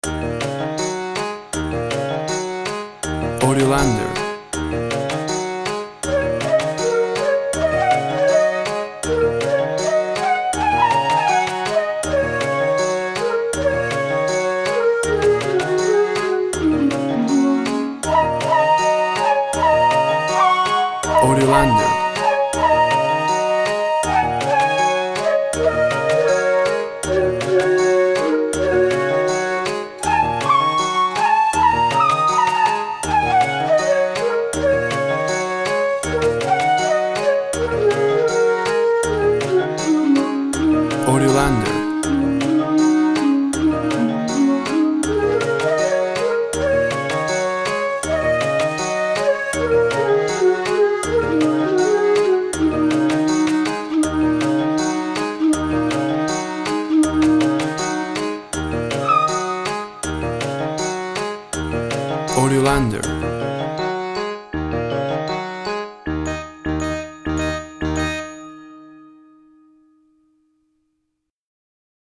Light-hearted jazzy instrumental song of the tropics.
Tempo (BPM) 160